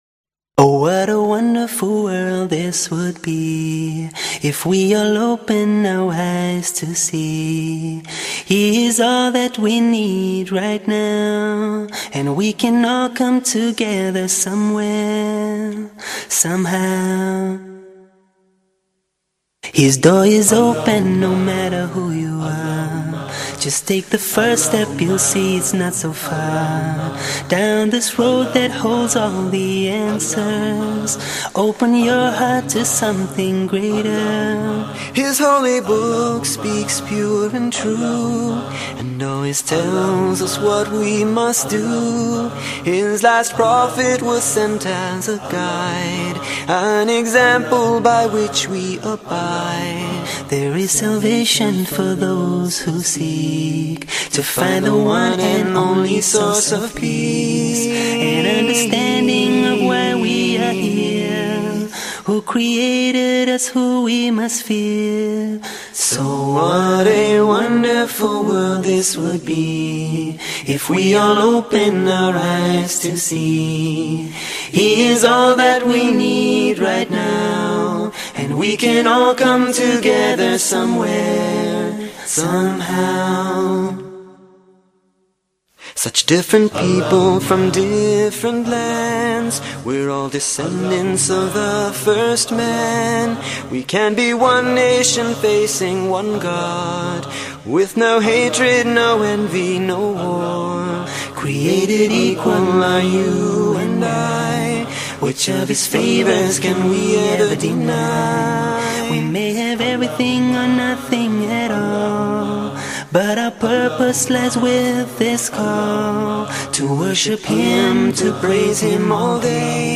Wonderful World – Nasheed